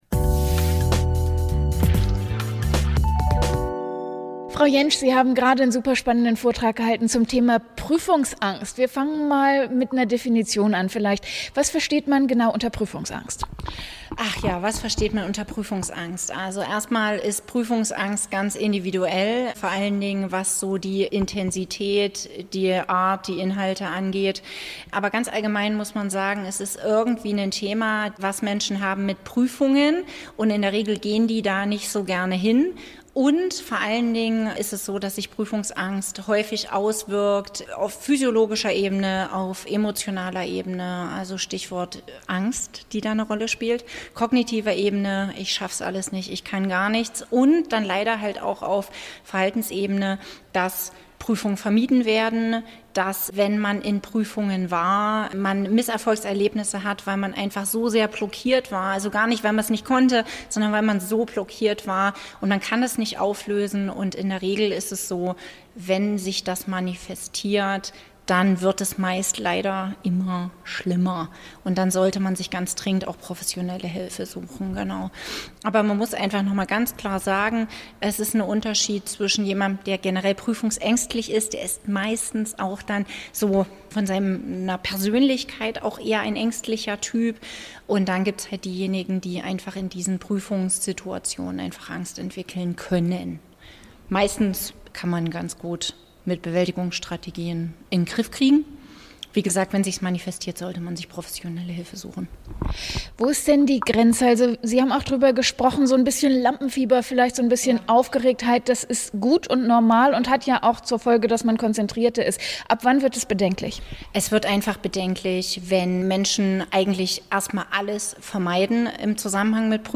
Interview mit Dipl.-Psych.